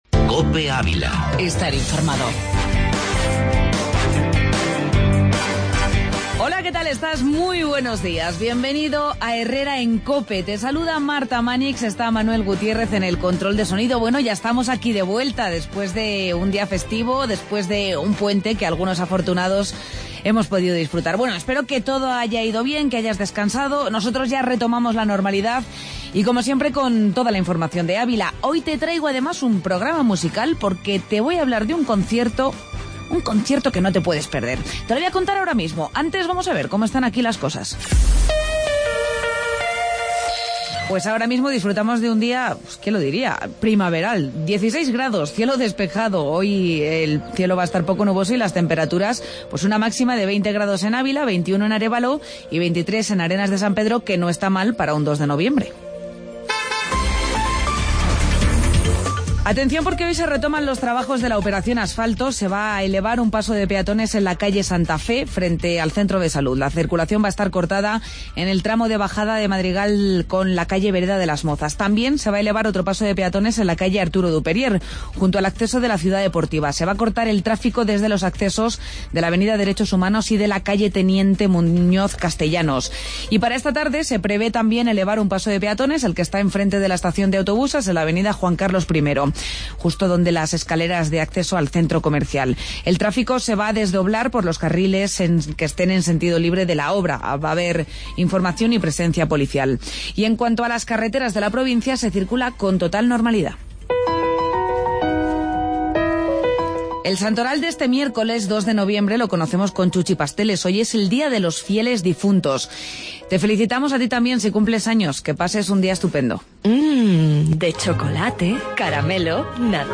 AUDIO: Entrevista Brother band